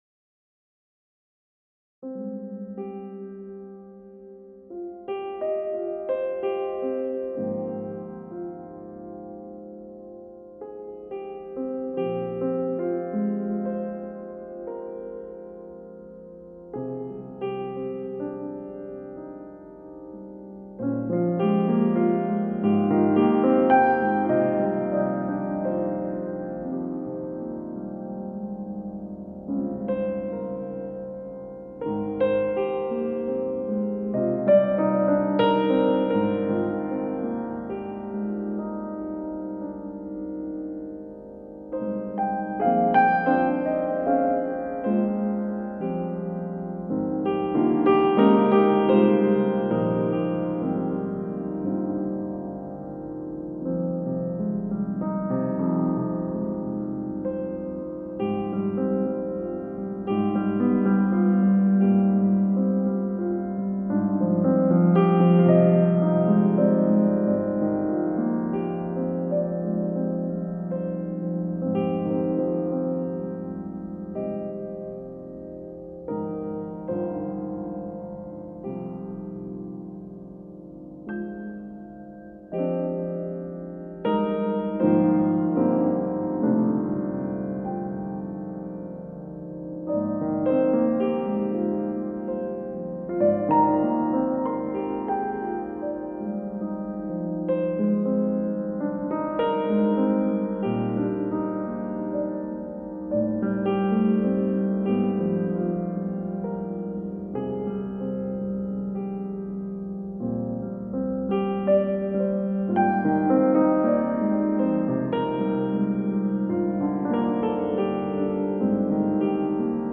ストリングス即興音楽